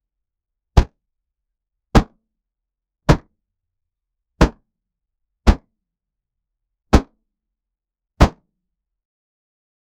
The heavy thud of a war hammer striking a shield.
the-heavy-thud-of-a-war-hammer-striking-a-shield-nw3sszlc.wav